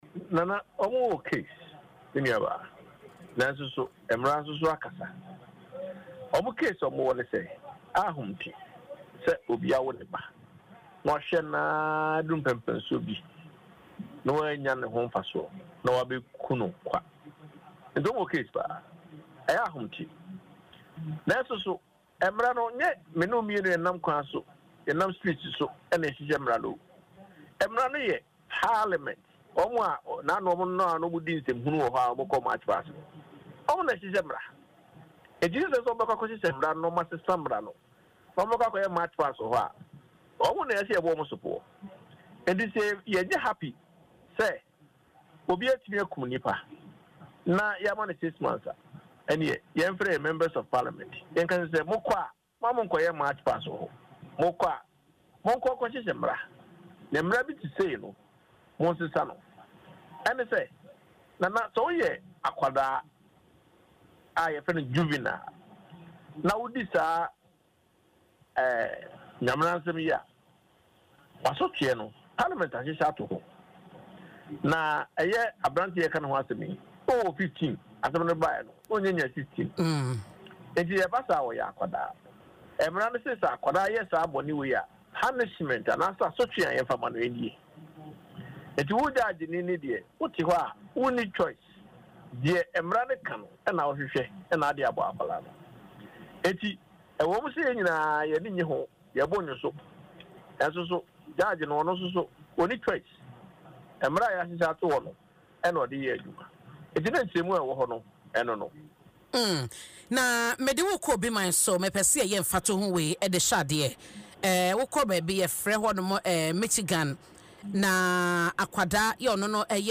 Speaking in an interview on Adom FM’s morning show Dwaso Nsem , he further suggested that the issue lies with lawmakers.